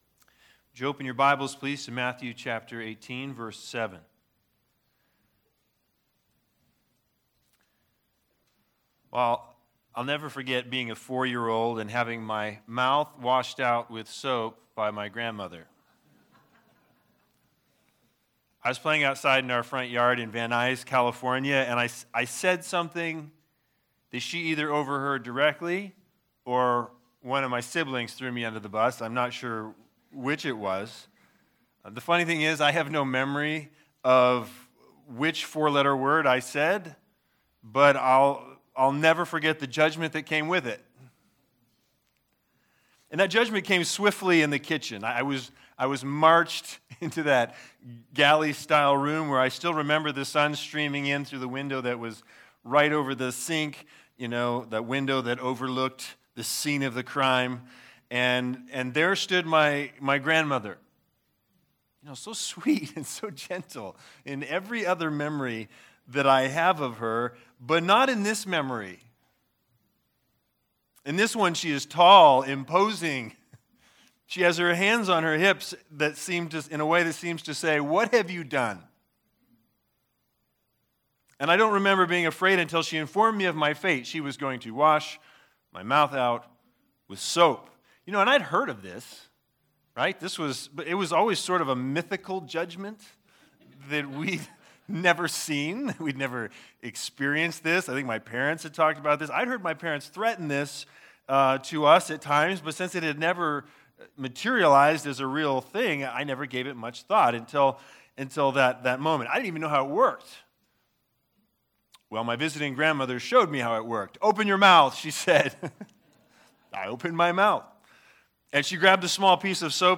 Matthew 18:7-9 Service Type: Sunday Sermons THE BIG IDEA